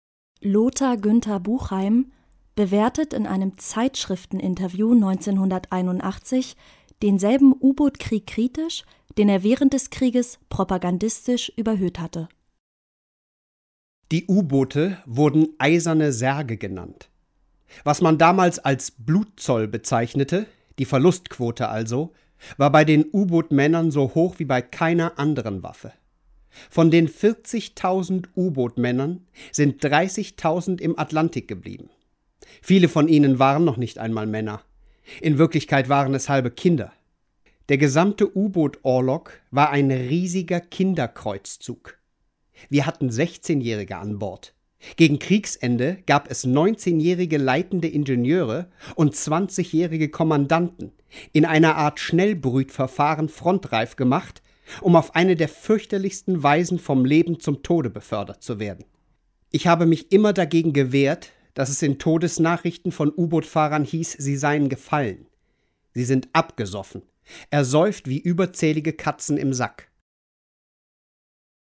Lothar-Günther Buchheim bewertet in einem Zeitschrifteninterview 1981 denselben U-Bootkrieg kritisch, den er während des Krieges propagandistisch überhöht hatte: